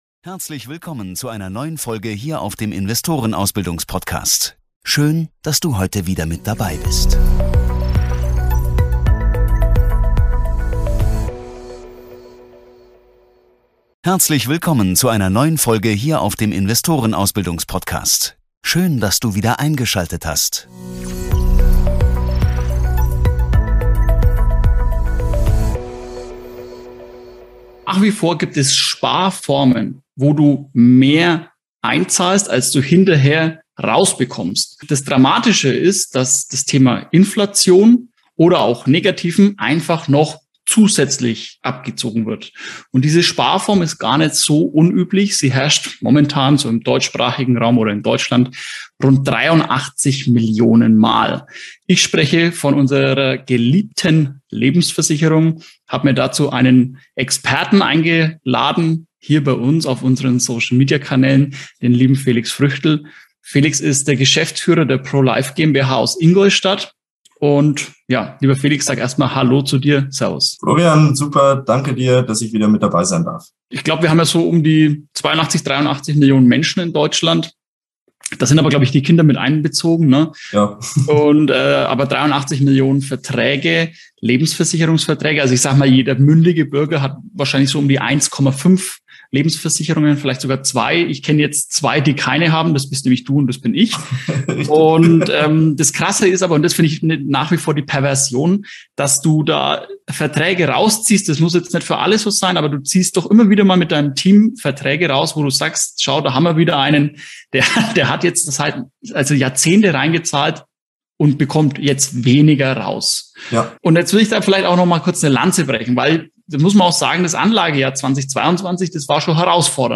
Lebensversicherung - wieso du keine abschließen solltest und was du dagegen tun kannst, falls du bereits eine hast, erfährst Du in diesem Interview.